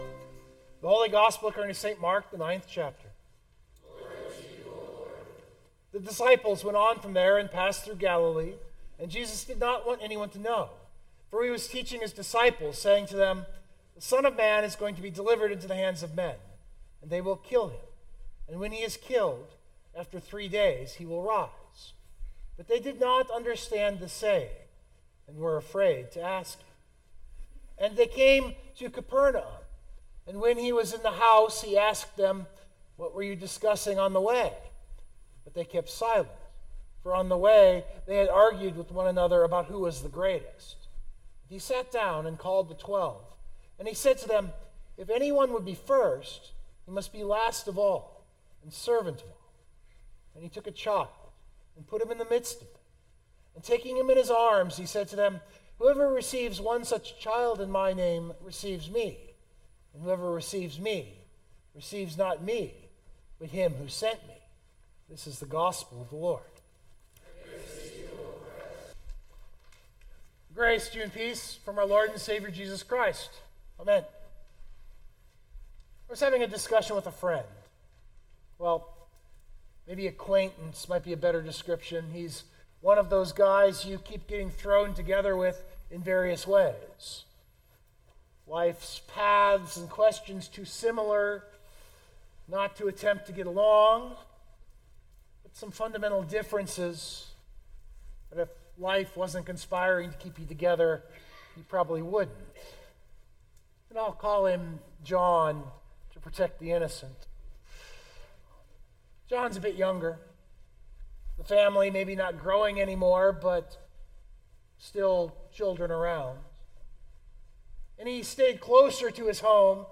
092224 Sermon Download Biblical Text